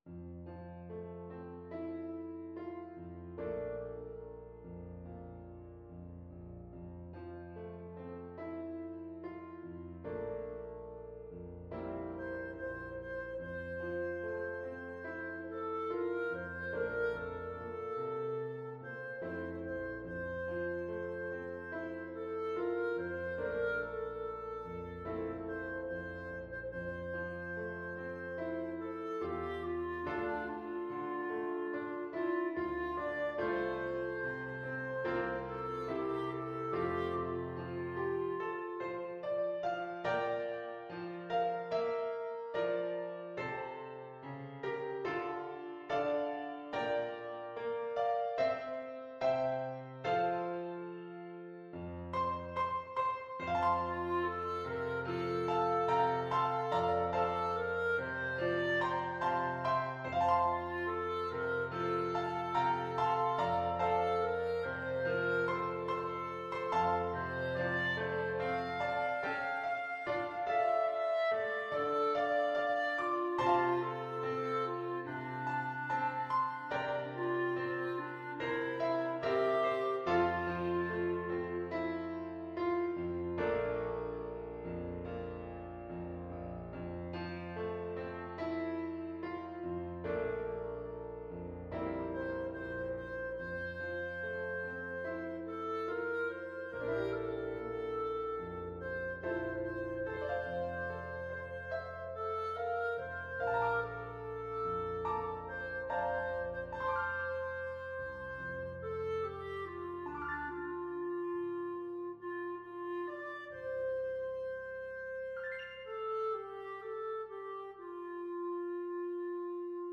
Molto espressivo =c.72
4/4 (View more 4/4 Music)
Classical (View more Classical Clarinet Music)